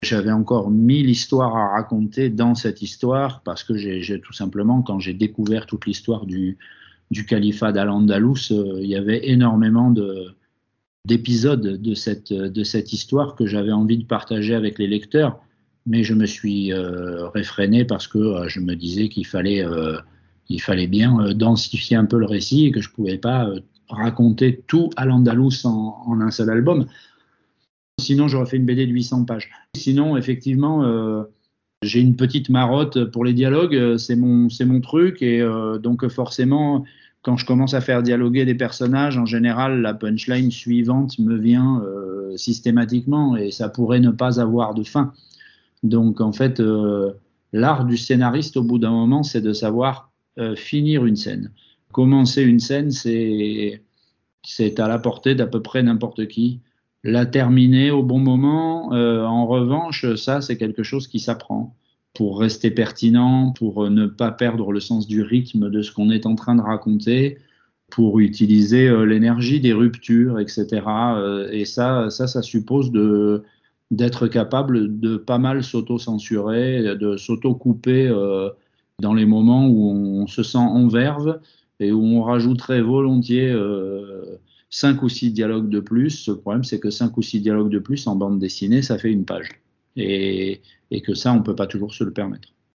entretien avec Wilfrid Lupano